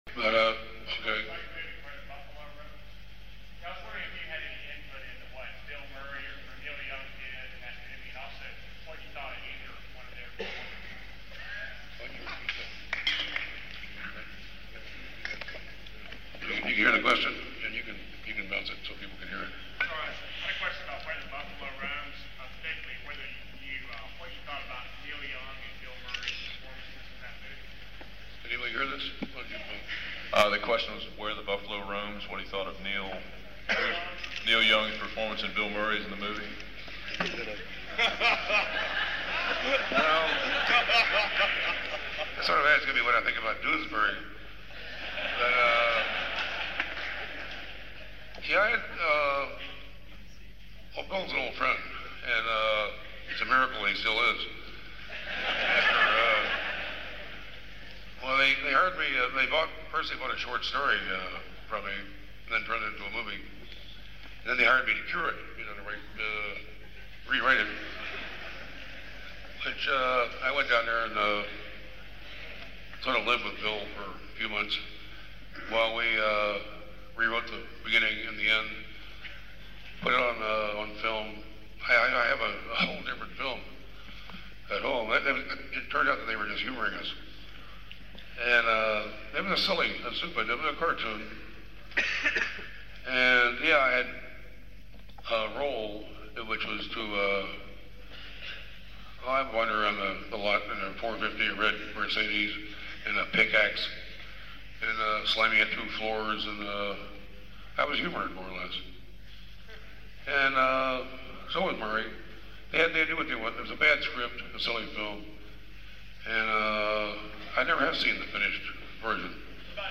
Live audio of Hunter S. Thompson at Washington & Lee University on April 4th 1991.